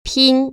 [pīn] 핀  ▶